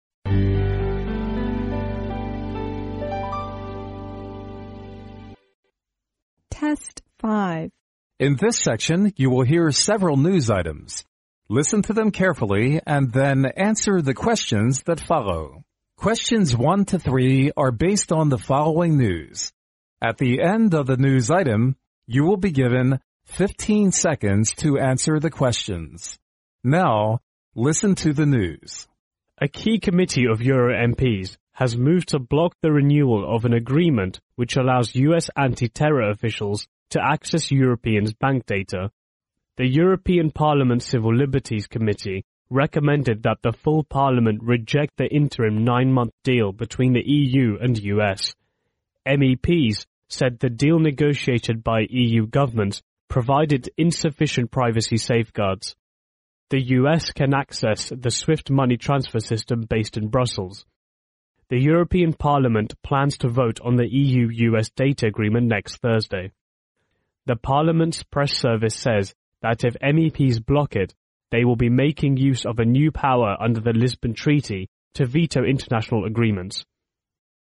News
Questions 1 to 3 are based on the following news. At the end of the news item，you will be given 15 seconds to answer the questions.